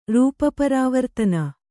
♪ rūpa parāvartana